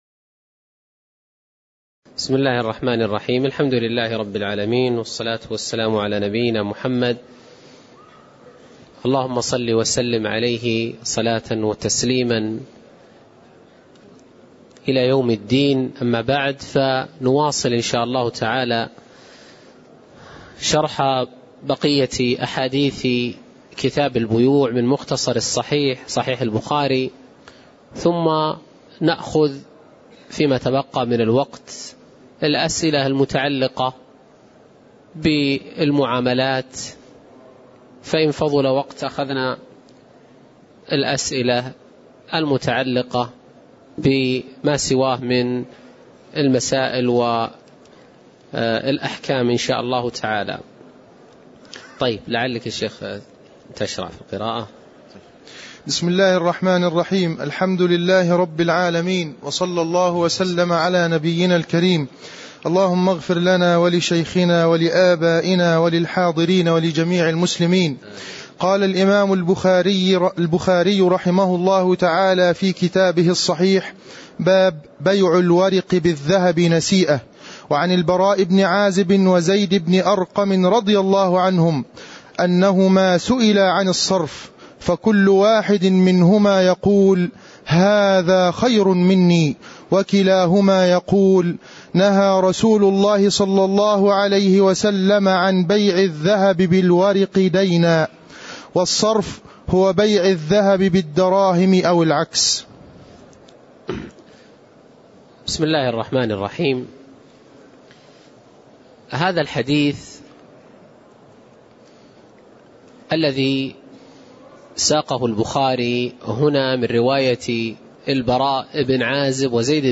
تاريخ النشر ٦ جمادى الأولى ١٤٣٨ هـ المكان: المسجد النبوي الشيخ